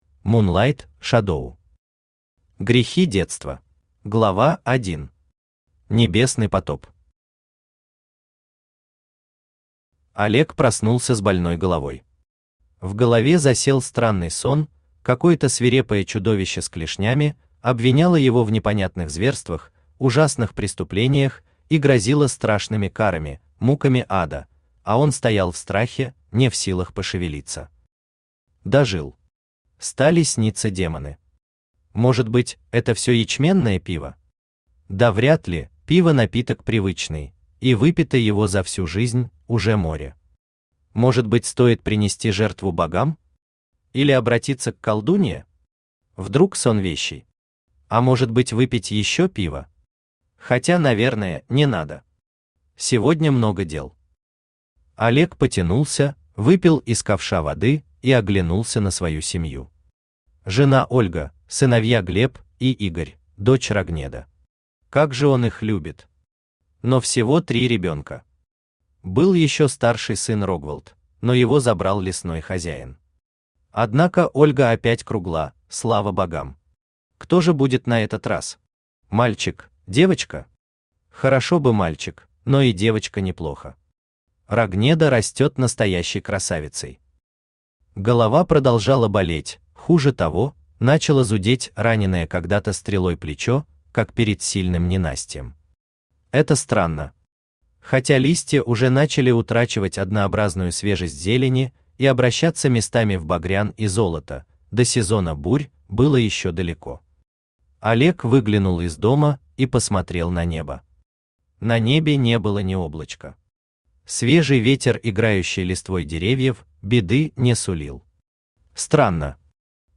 Аудиокнига Грехи детства | Библиотека аудиокниг
Aудиокнига Грехи детства Автор Мунлайт Шадоу Читает аудиокнигу Авточтец ЛитРес.